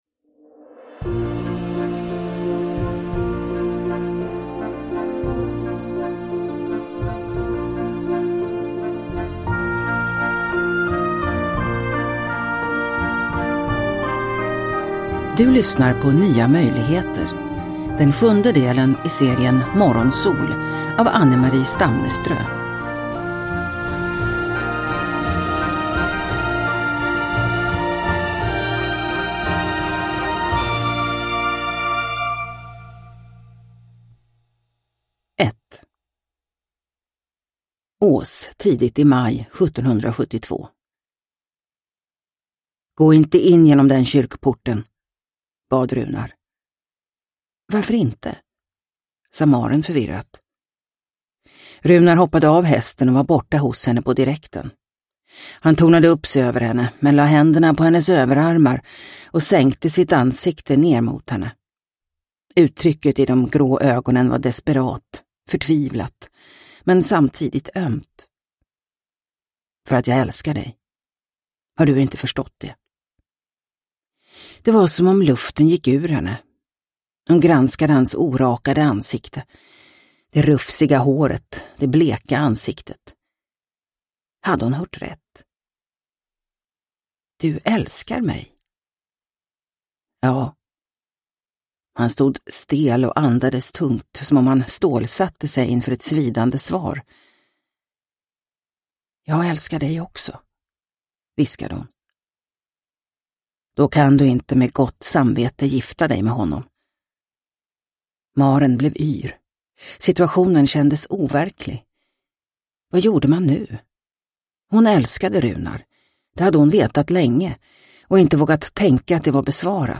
Nya möjligheter – Ljudbok – Laddas ner